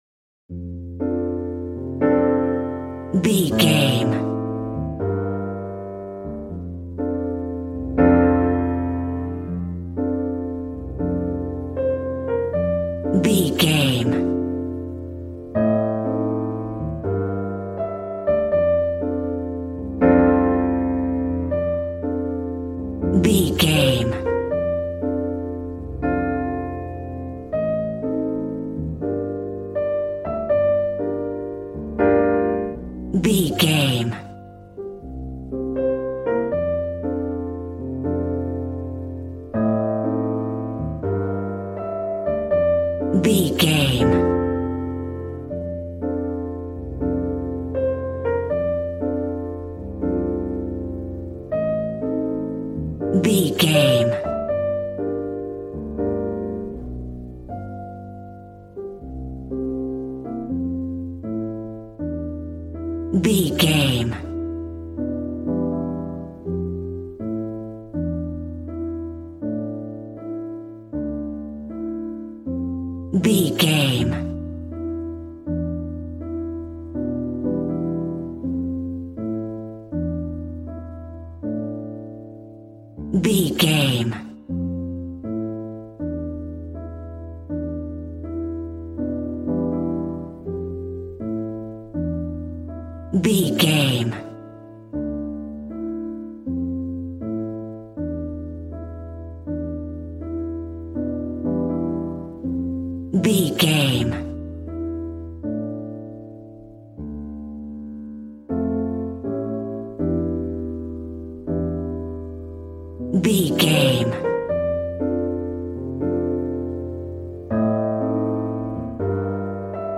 Smooth jazz piano mixed with jazz bass and cool jazz drums.,
Aeolian/Minor
D
piano
drums